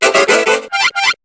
Cri de Chrysapile dans Pokémon HOME.